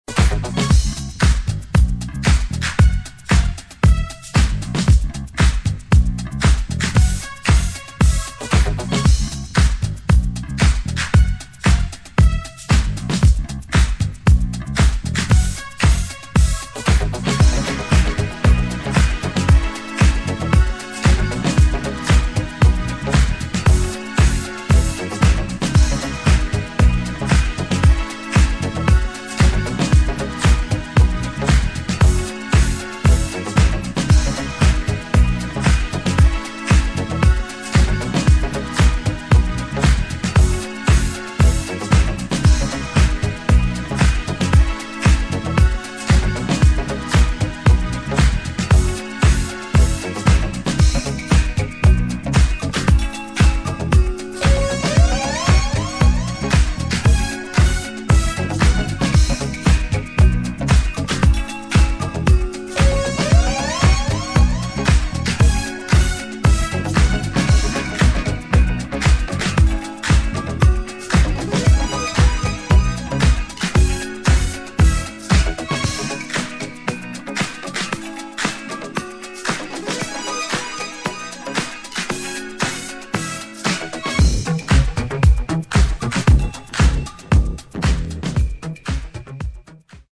HOUSE | DISCO
[ DISCO | EDIT ]